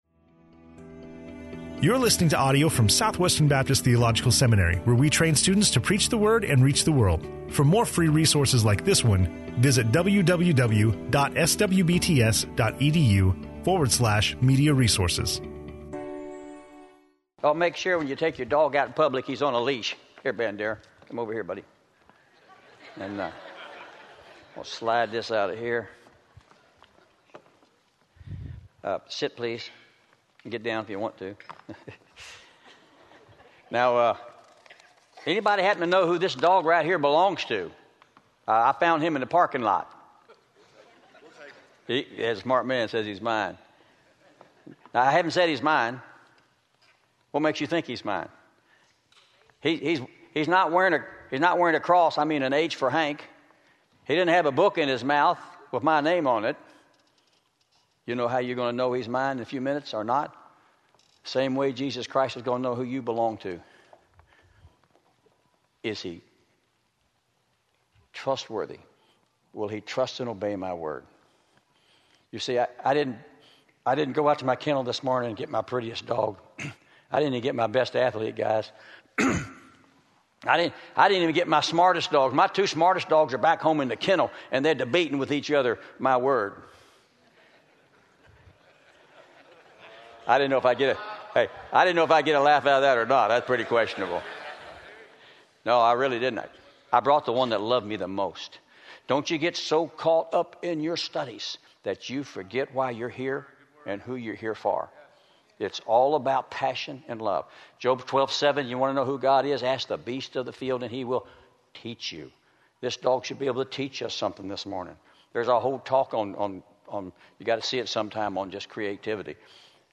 in SWBTS Chapel on Tuesday October 7, 2014